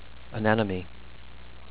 an-NEM-on-ee